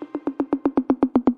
Southside Percussion (13).wav